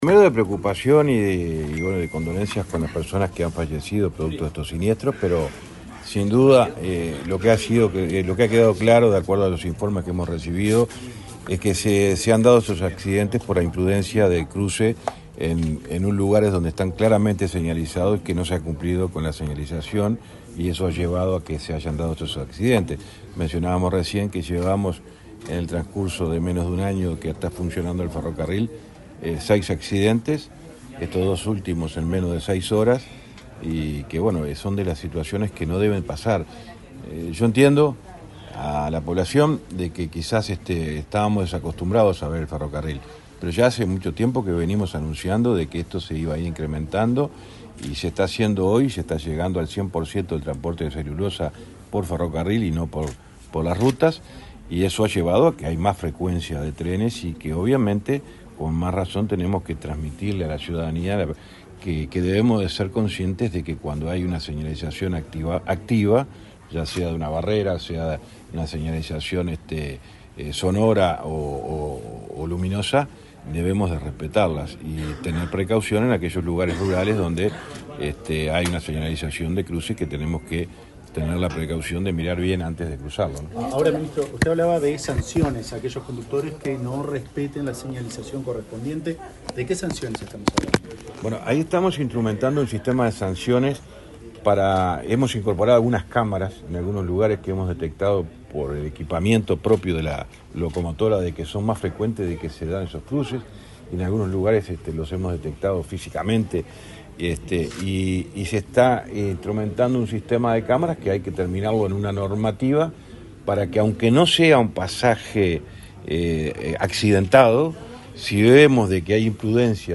Declaraciones del ministro de Transporte, José Luis Falero
Declaraciones del ministro de Transporte, José Luis Falero 18/02/2025 Compartir Facebook X Copiar enlace WhatsApp LinkedIn El ministro de Transporte, José Luis Falero, realizó una conferencia de prensa, para informar acerca de la operativa ferroviaria y las medidas adoptadas a raíz de los accidentes ocurridos en las vías y los pasos de ese medio de transporte. Luego, dialogó con la prensa.